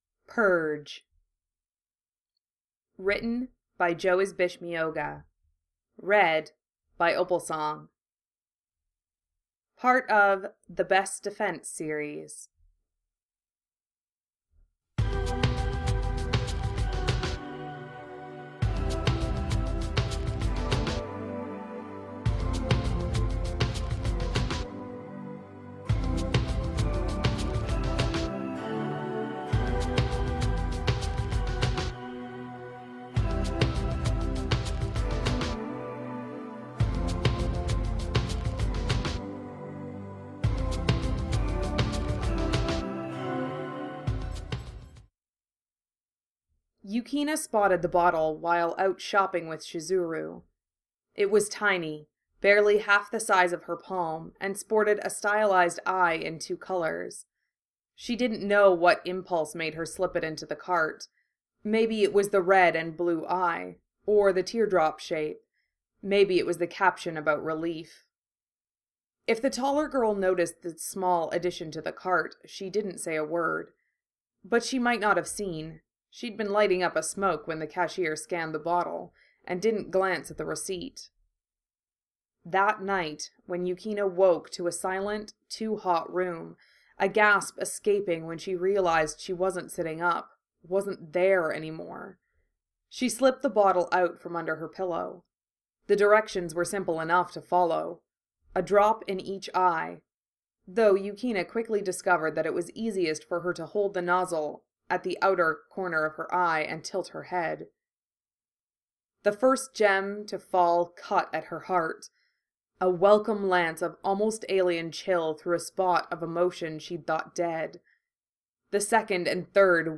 Music: Tatakai no Hate from Yu Yu Hakusho